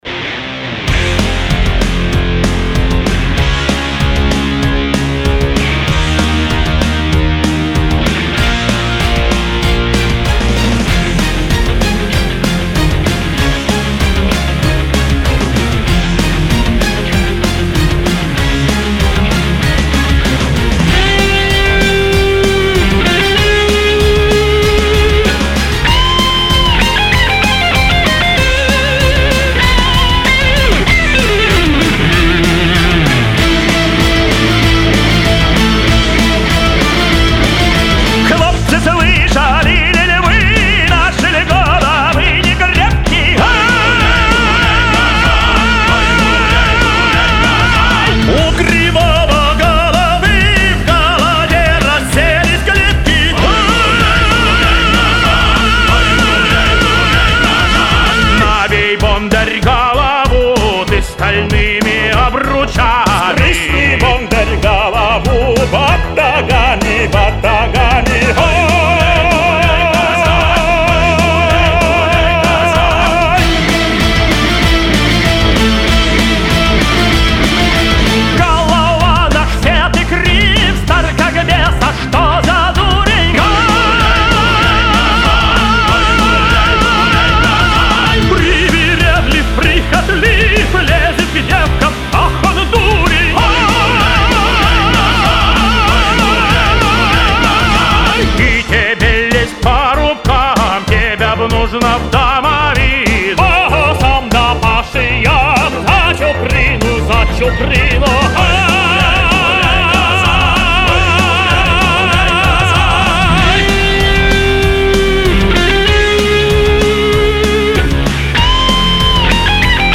Студийные записи